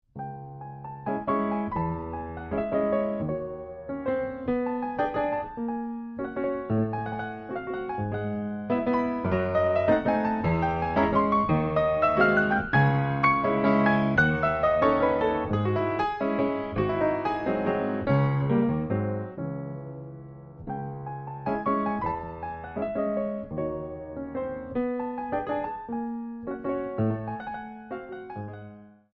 Valzer venezuelani del XIX sec.
Pianoforte
Registrazione audiophile realizzata nei mesi di agosto e settembre 2005 con microfoni e pre-amplificatore a valvole, campionamento a 96 kHz.